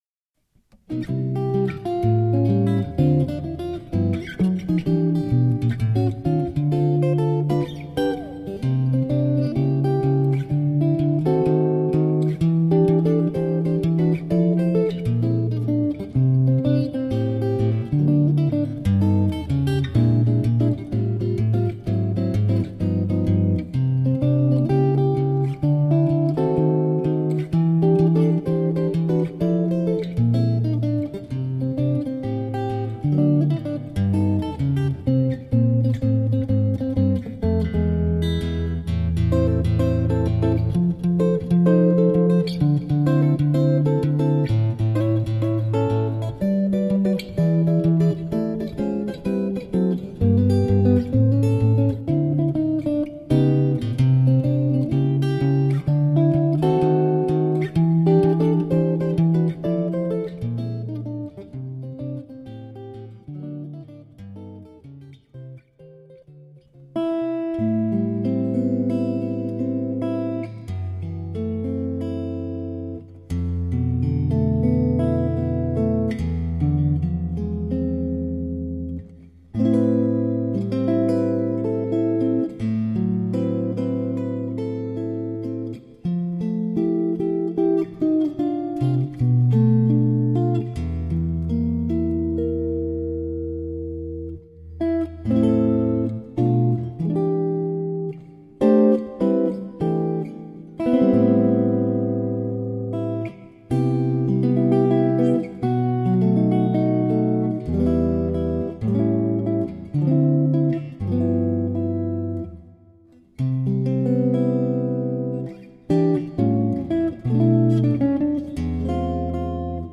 Sologitarre mit musikalischen Dialogen verschiedenster Art.
Bossa Nova, Uptempo Bebop, Jazz Blues und Balladen vereint.
Bilanz: Eine Mainstream Hommage an die Grossen